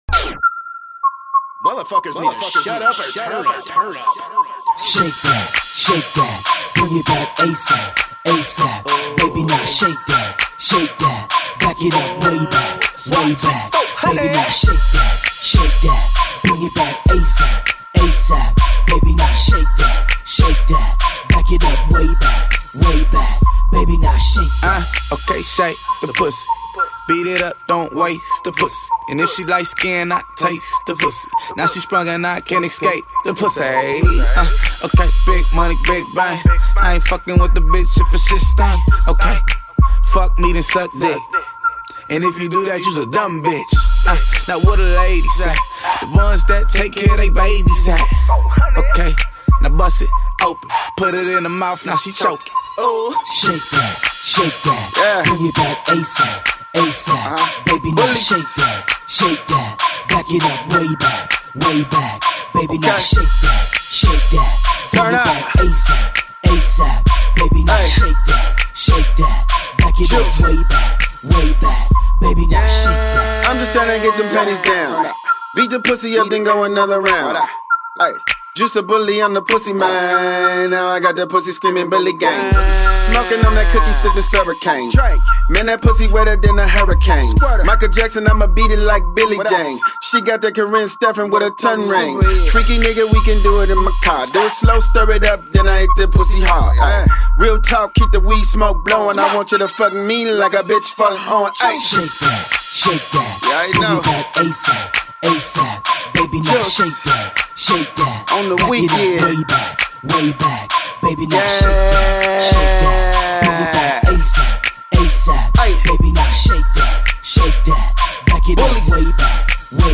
West East South rap. Crunk. Hip hop & rap.